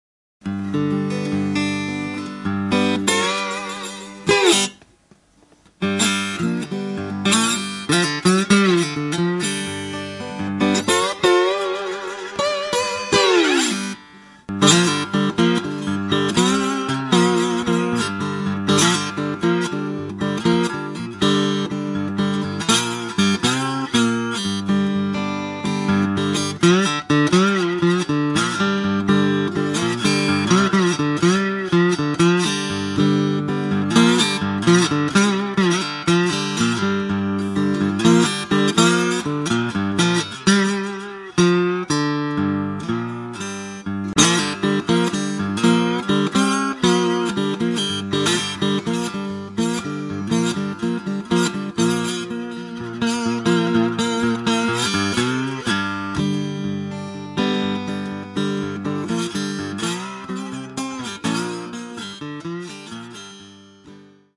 Basically, a warm, very gentle piece of guitar playing.